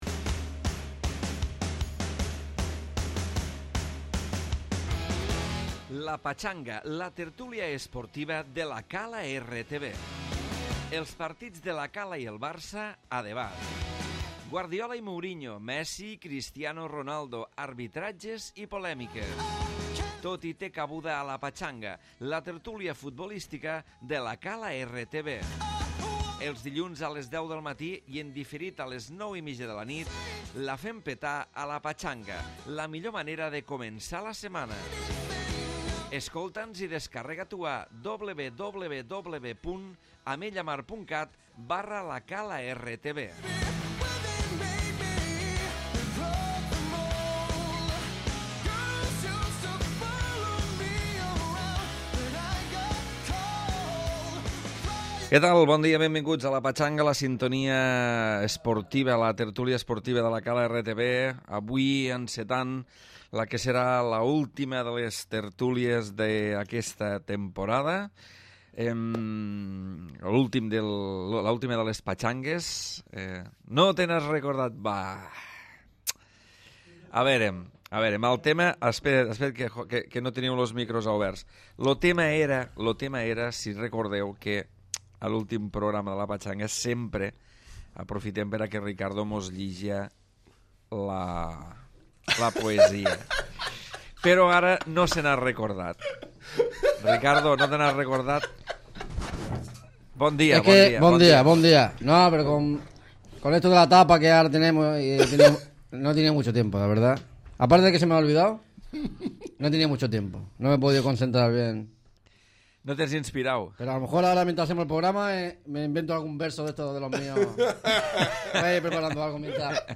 Darrera tertúlia de la temporada. La Patxanga s'acomiada de l'antena fins al mes de setembre.